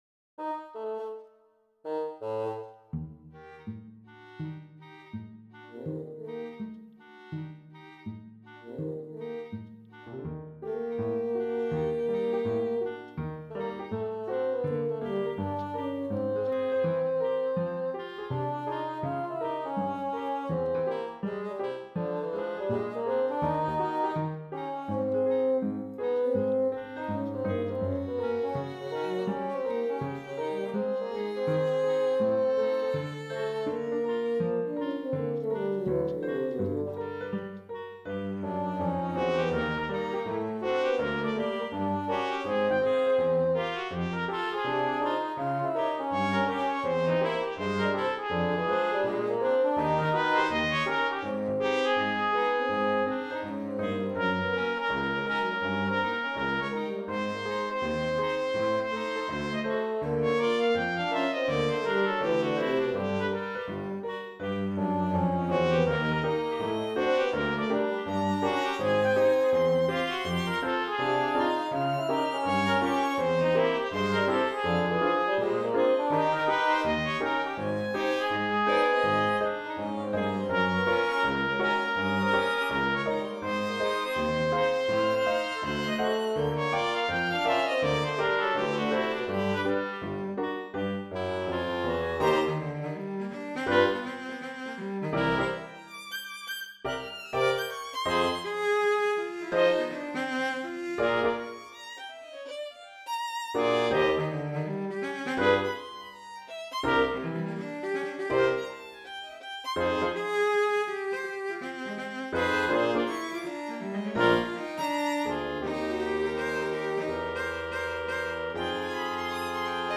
Op.28 pour sextuor avec piano (Trompette, Clarinette, basson, violon, violoncelle et piano)
Maquette audio